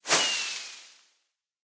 minecraft / sounds / fireworks / launch1.ogg
launch1.ogg